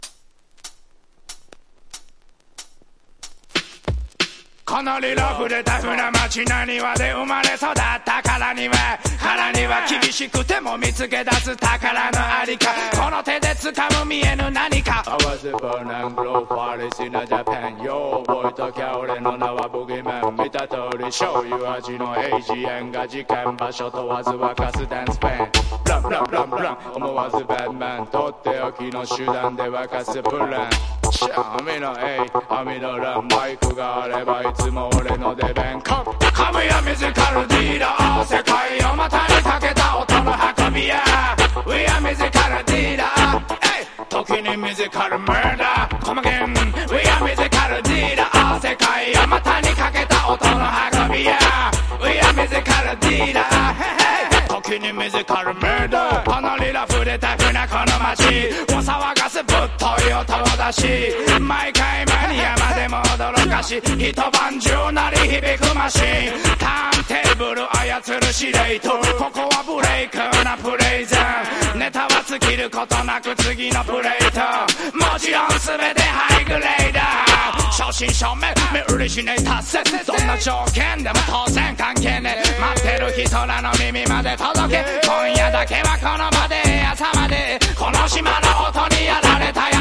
• REGGAE-SKA
REGGAE / SKA / DUB# DANCE HALL# REGGAE